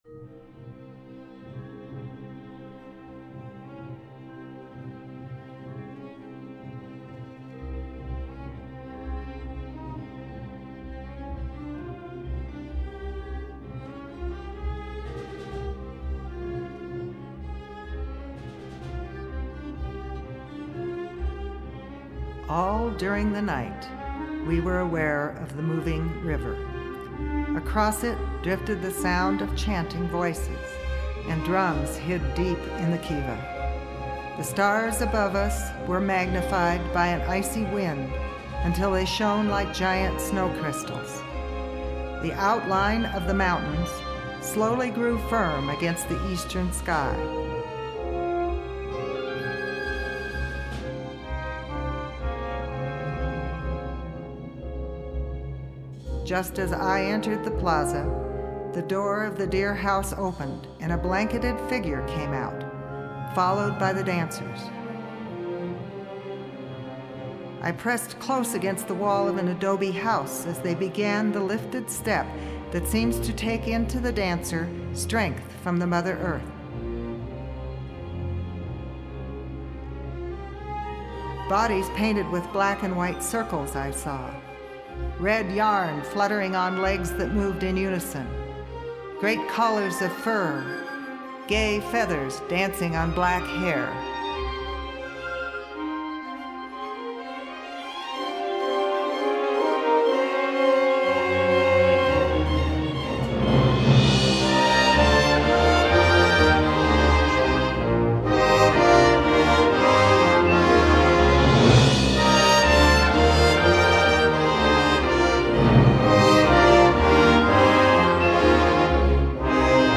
Suite for Narrator and Orchestra